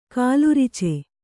♪ kālurice